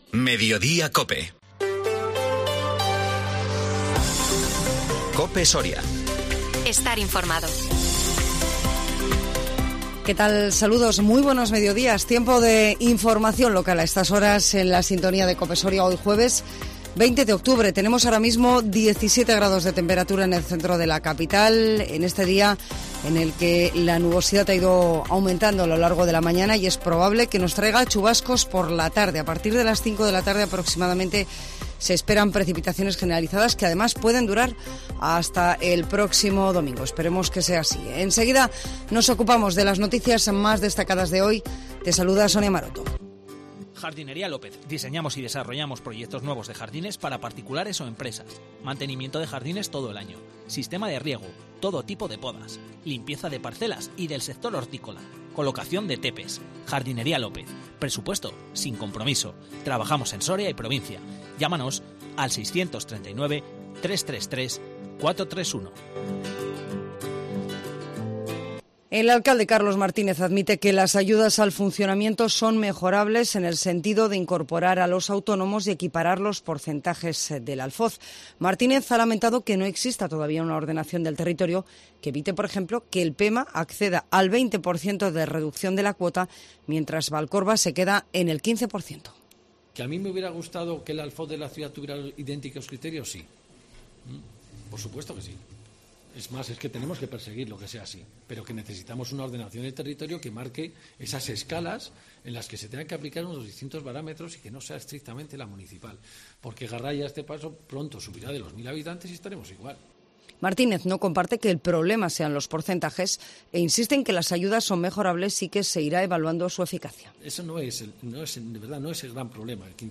INFORMATIVO MEDIODÍA COPE SORIA 20 OCTUBRE 2022